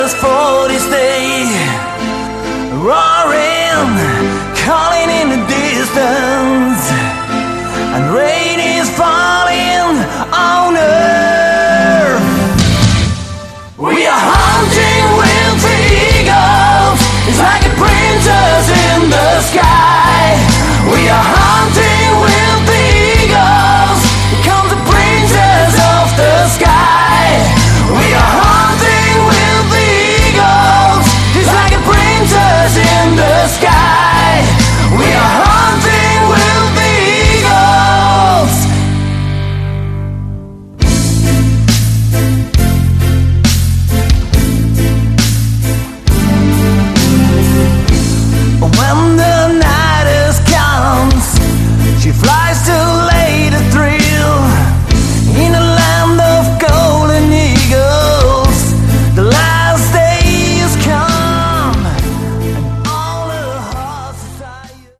Category: melodic hard rock
Vocals
Guitars
Bass
Keyboards
Drums